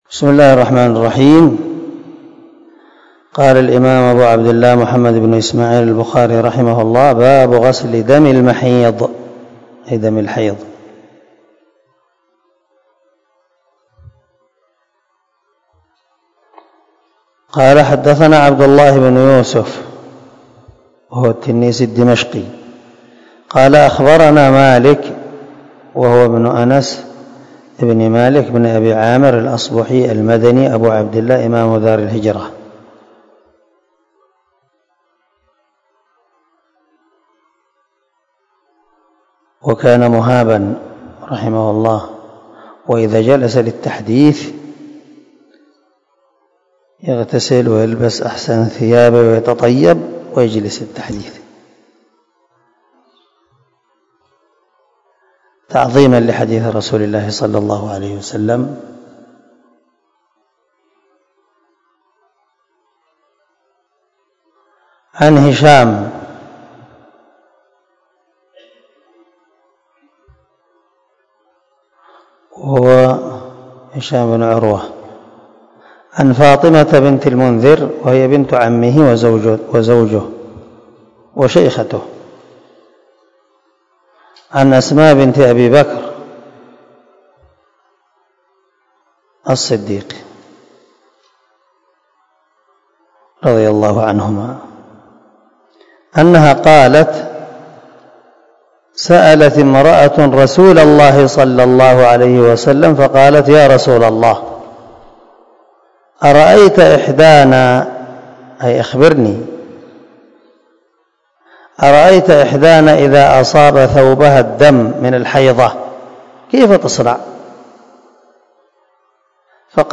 241الدرس 8 من شرح كتاب الحيض حديث رقم ( 307 – 308 ) من صحيح البخاري
دار الحديث- المَحاوِلة- الصبيحة.